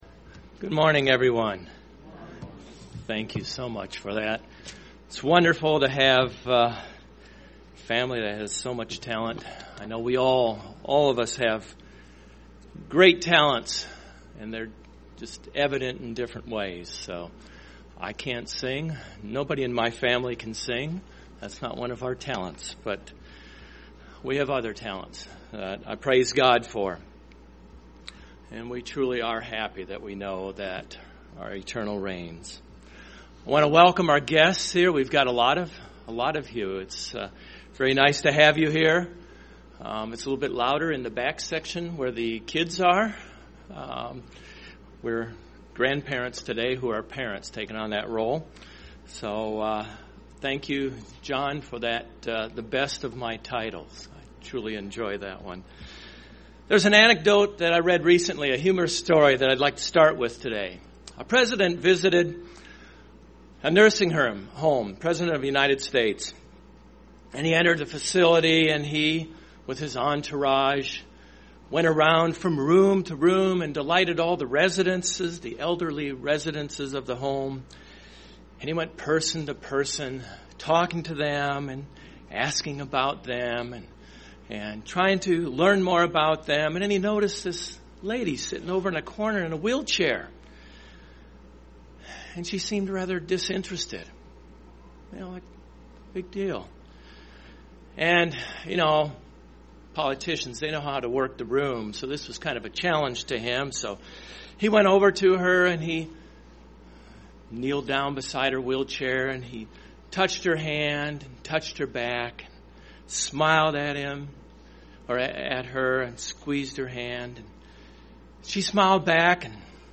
Given in Cincinnati East, OH
UCG Sermon Studying the bible?